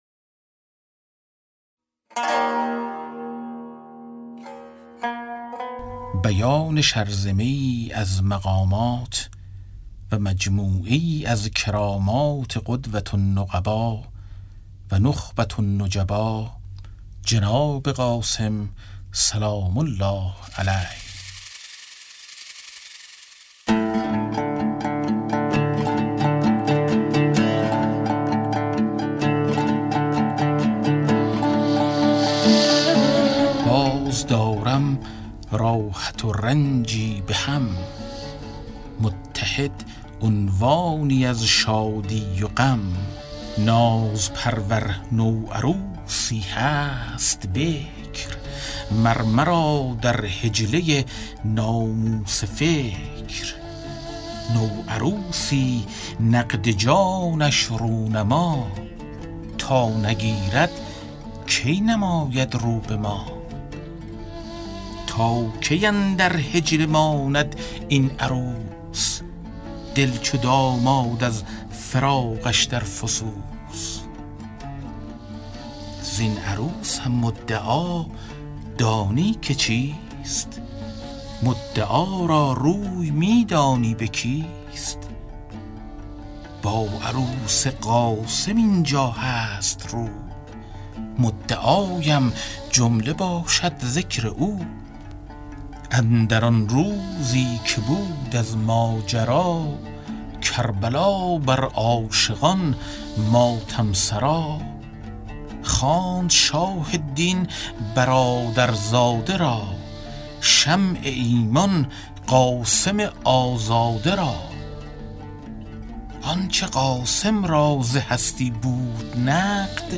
کتاب صوتی گنجینه‌الاسرار، مثنوی عرفانی و حماسی در روایت حادثه عاشورا است که برای اولین‌بار و به‌صورت کامل در بیش از 40 قطعه در فایلی صوتی در اختیار دوستداران ادبیات عاشورایی قرار گرفته است.